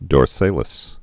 (dôr-sālĭs, -sălĭs)